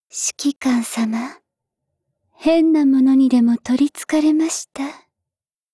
（ 留言 | 贡献 ） 协议：Copyright，人物： 碧蓝航线:扶桑·META语音 您不可以覆盖此文件。